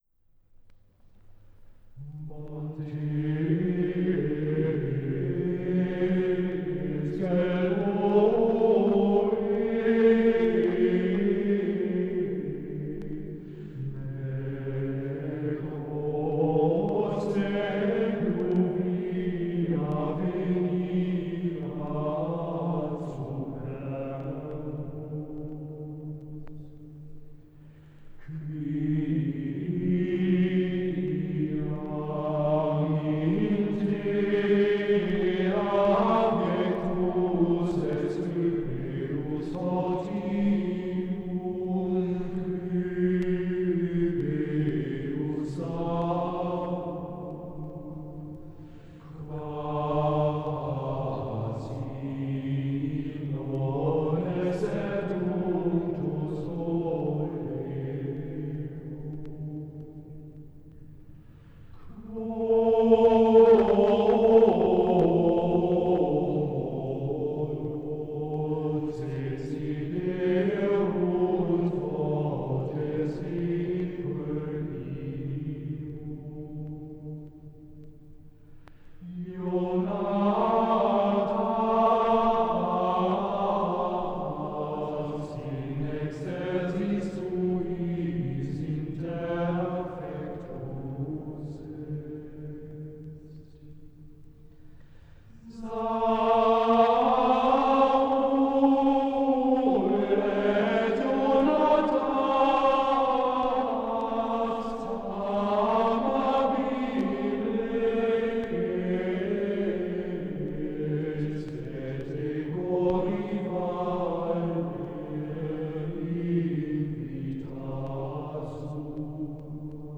Antiphon
Gesang: Schola gregoriana im Auftrag der Robert-Schumann-Hochschule Düsseldorf
aufgenommen in der Klosterkirche Knechtsteden